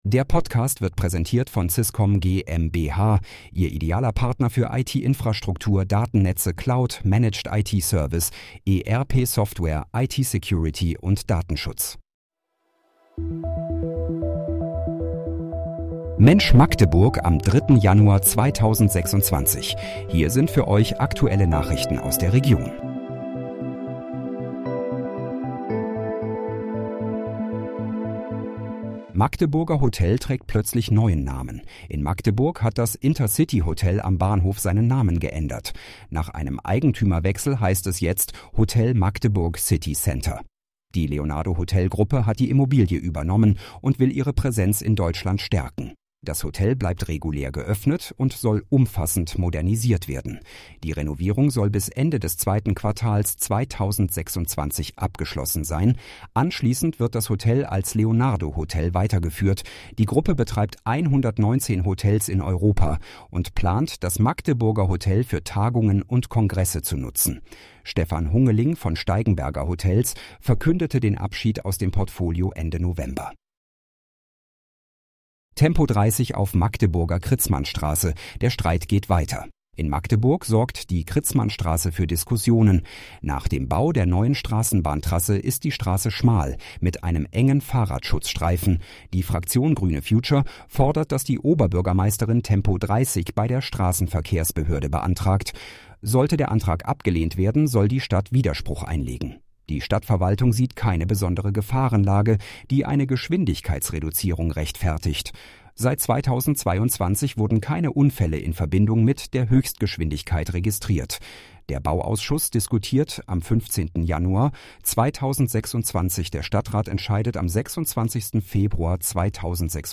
Mensch, Magdeburg: Aktuelle Nachrichten vom 03.01.2026, erstellt mit KI-Unterstützung